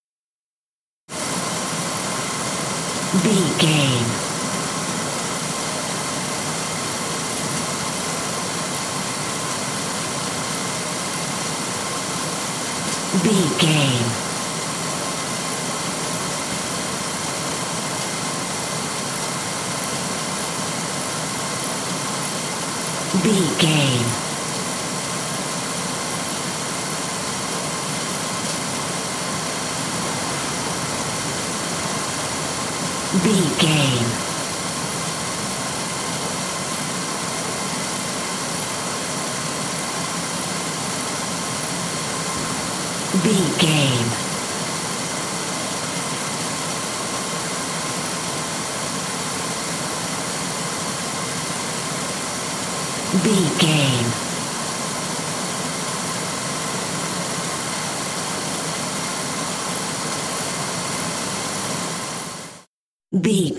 Airport baggage carousel cargo
Sound Effects
urban
airport sounds